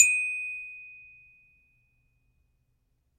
Glockenspiel » c1
描述：c1 glockenspiel note in mono, recorded using an AKG c3000b and straight into my 'puter.Part of a set. The glockenspiel is a 3 octave educational type, not abig orchestral one, so the octave numbers supplied are actually about 4octaves lower than true
标签： instrument note pitched short unprocessed